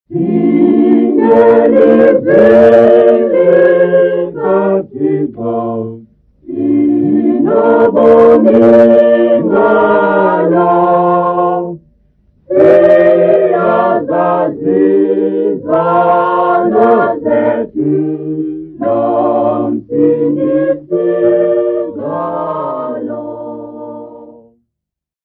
Church congregation
Folk music
Sacred music
Field recordings
Africa South Africa Lumko, Eastern Cape sa
sound recording-musical
Hymn number 261 from new Xhosa Prayer Book
7.5 inch reel